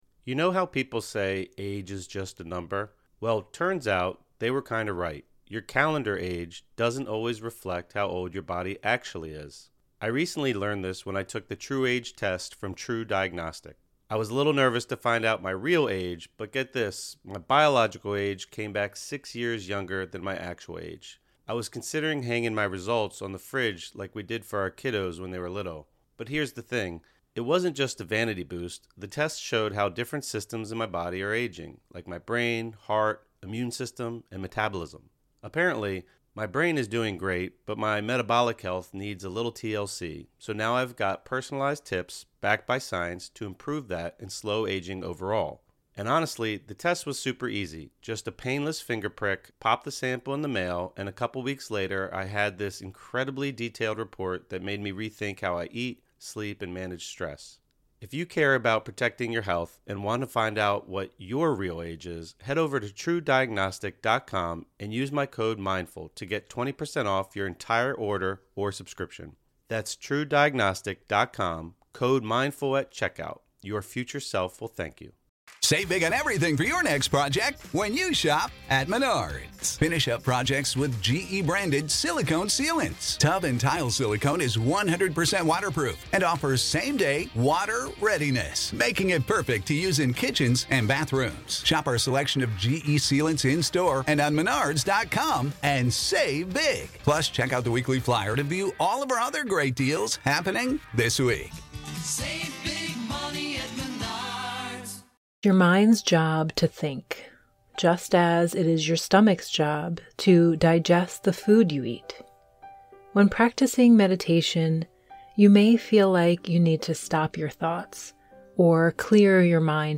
The meditation will leave you feeling grounded, empowered, and at peace, with a reminder of your inner resilience and strength. This practice focuses on nurturing your core power and stability. Meditation